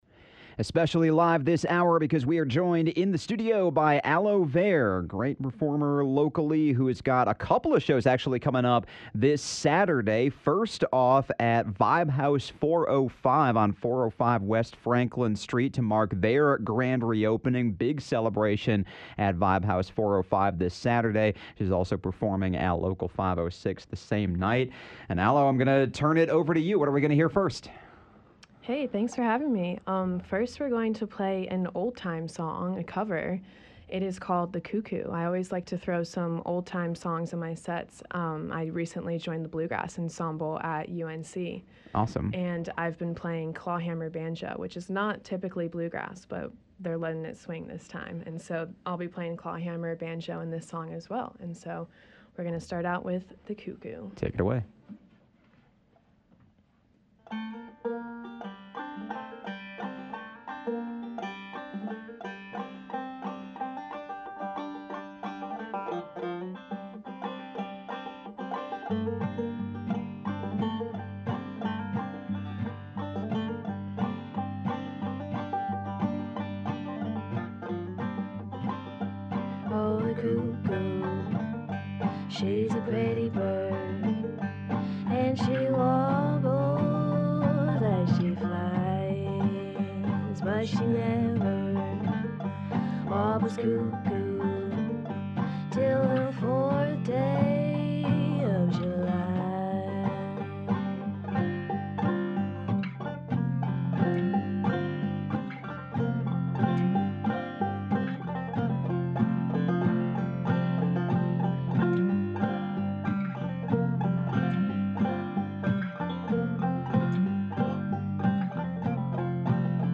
layered vocals on experimental beats
conversation